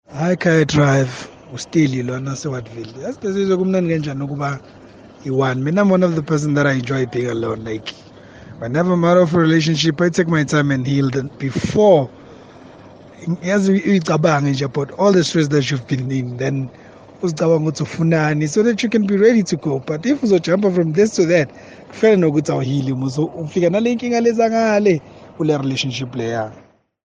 Here’s what Kaya Drive listeners had to say: